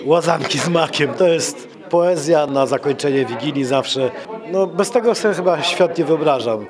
To już piękna świąteczna tradycja – wigilia w Radiu 5 Ełk.
Marek Chojnowski – starosta powiatu ełckiego – mówi, że łazanki to żelazny punkt wigilijnego menu.